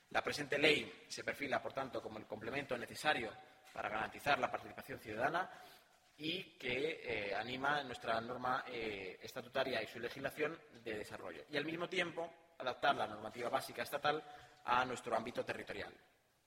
corte_portavoz_transparencia_1.mp3